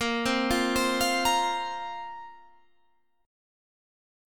A#sus2 Chord
Listen to A#sus2 strummed